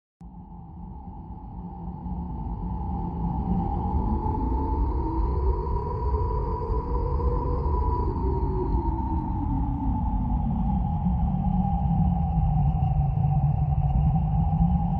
Wind Howling
Wind Howling is a free ambient sound effect available for download in MP3 format.
003_wind_howling.mp3